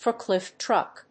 アクセントfórklift trùck